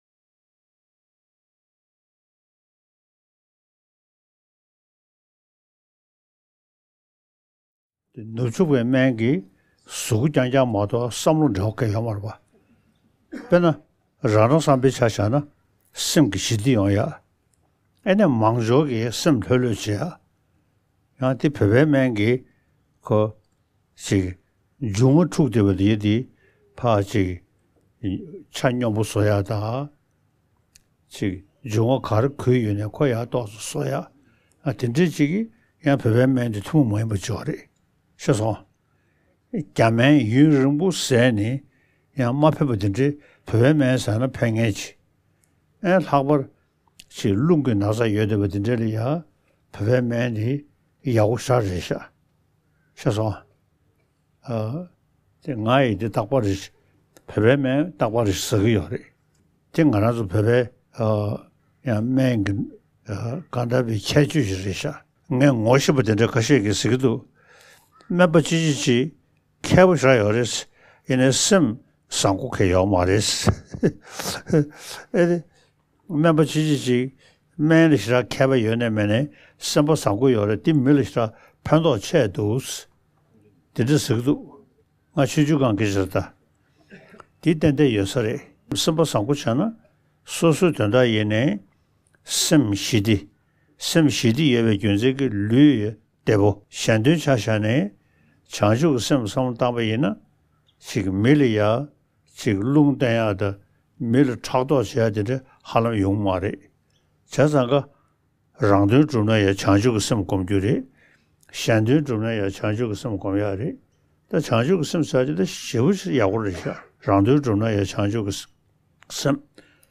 ཕྱི་ལོ་ ༢༠༢༣ ཟླ་ ༣ ཚེས་ ༢༩ ཉིན་བསྩལ་བའི་བཀའ་སློབ།